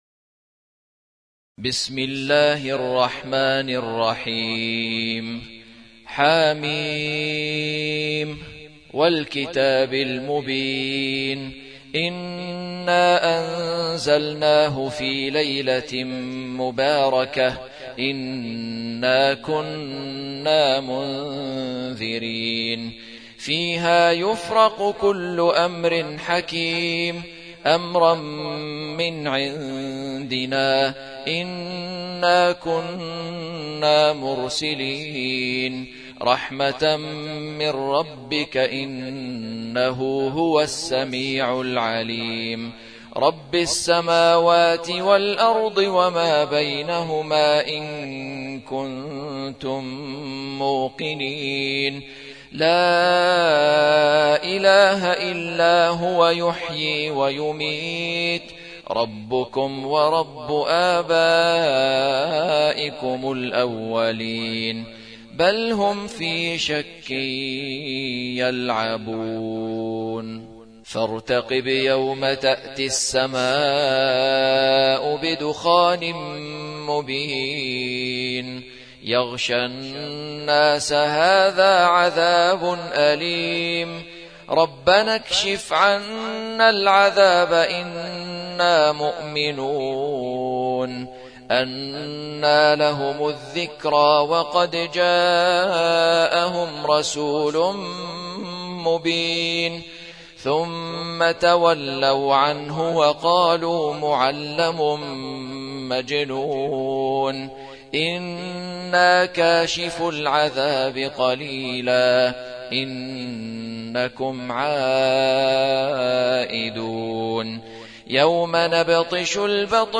القرآن الكريم